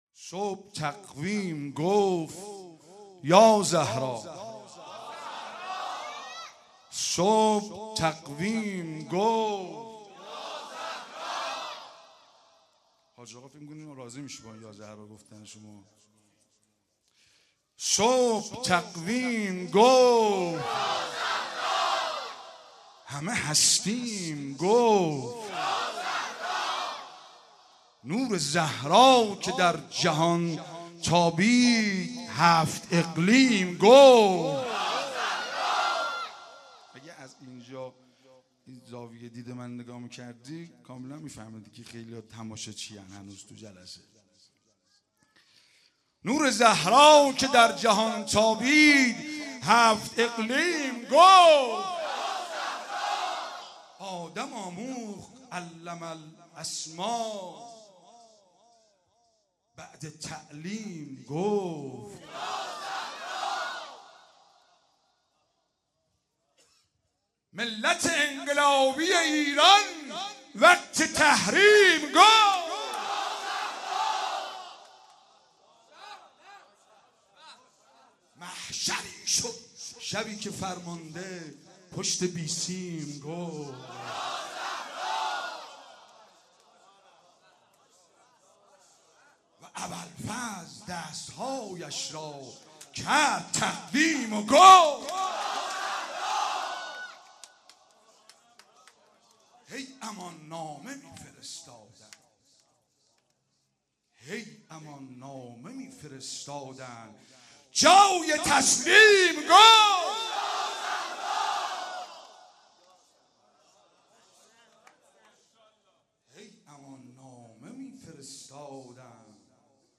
مدح آذری